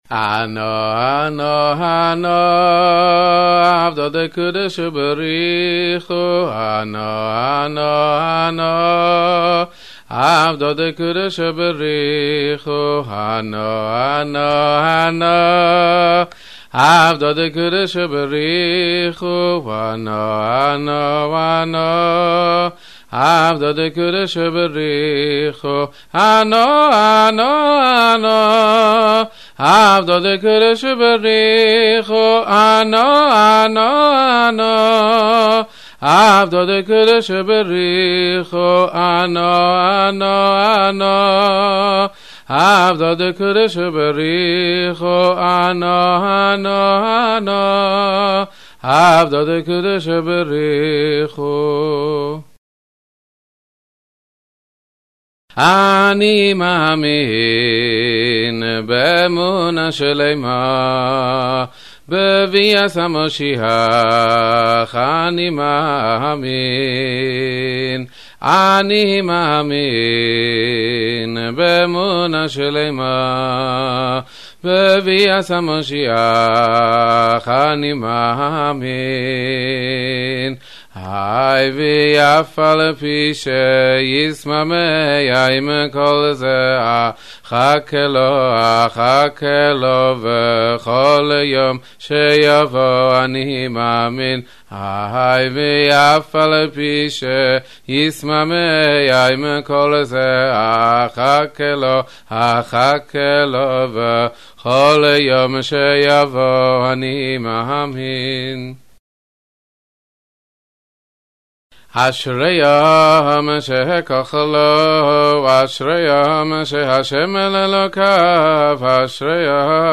Sing along with 32 of the most popular songs for Simchat Torah.
A mainstay of this celebration is the singing of traditional songs – again and again, as we dance around the bima.